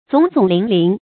总总林林 zǒng zǒng lín lín
总总林林发音